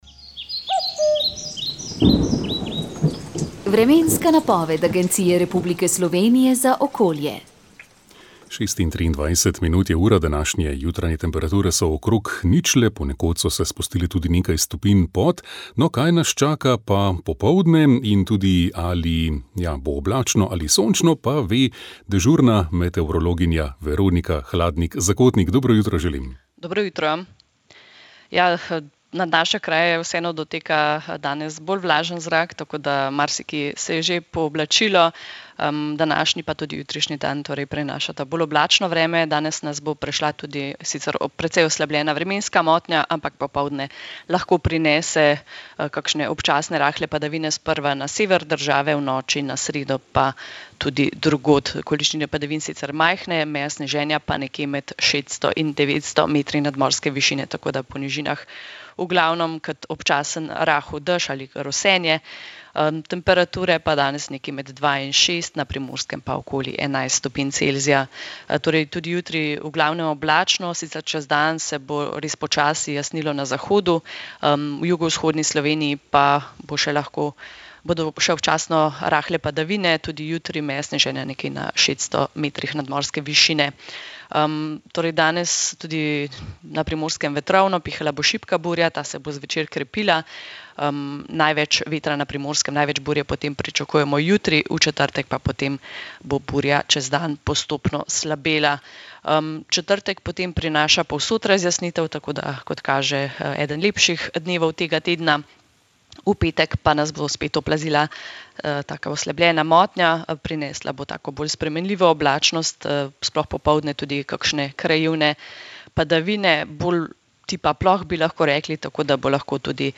Praznični utrip med rojaki v Avstraliji, Združenih državah Amerike in Nemčije so nam predstavili slovenski duhovniki